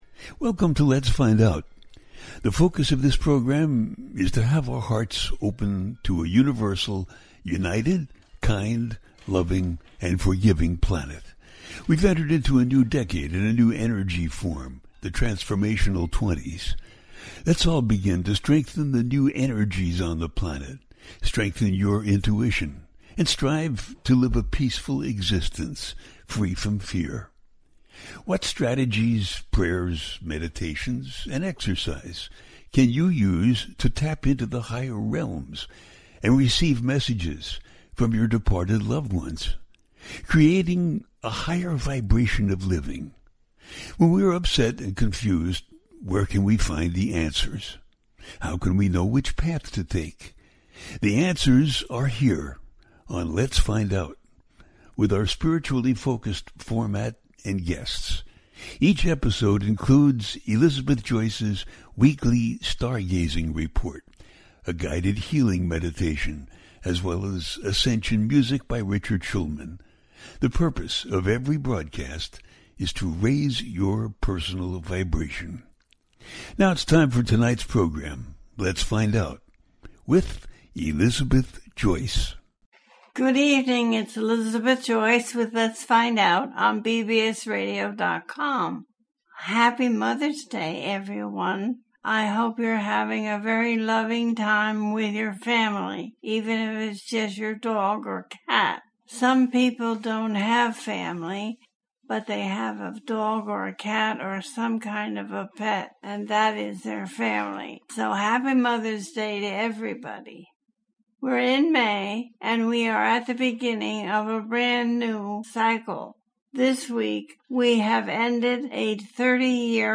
The Spiritual Importance Of May - A teaching show